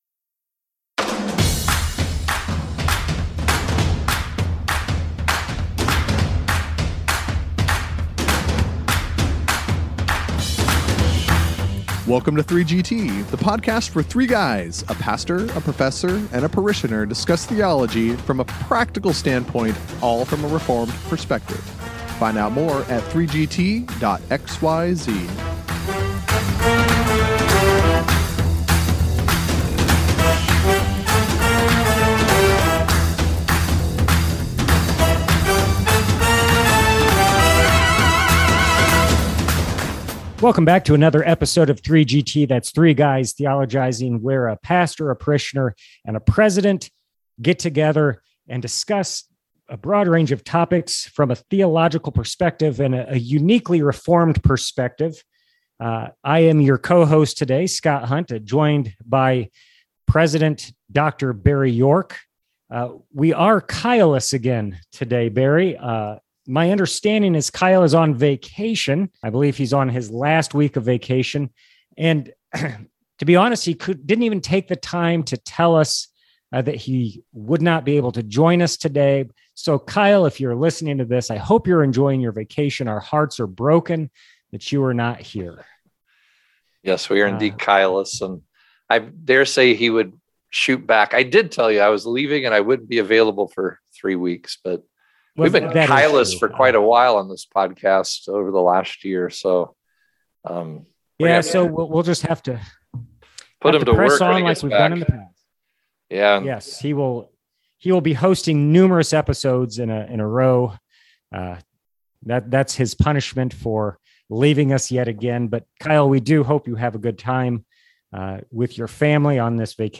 the parishioner and professor tackle a subject the solo pastor may not have wanted to discuss. What about congregations having more than one pastor?